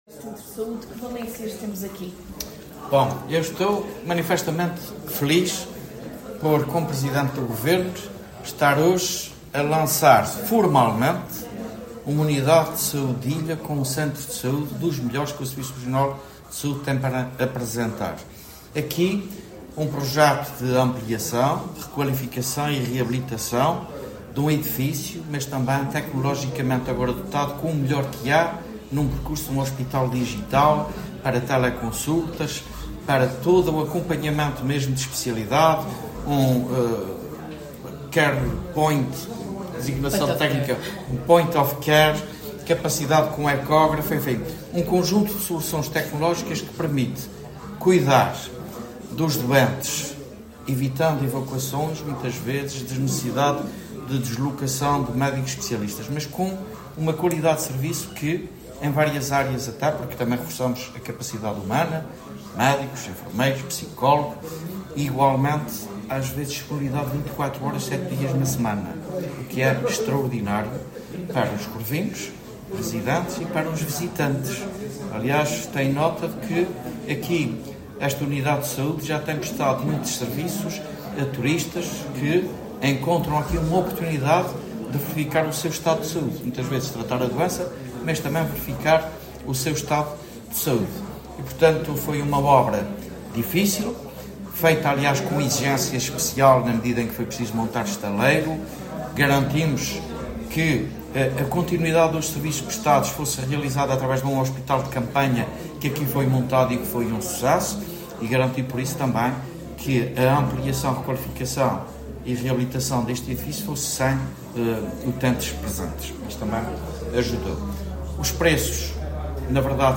José Manuel Bolieiro falava durante a cerimónia de inauguração da requalificação da Unidade de Saúde, acompanhado da Secretária Regional da Saúde, Mónica Seidi, e da Secretária Regional do Turismo, Mobilidade e Infraestruturas, Berta Cabral.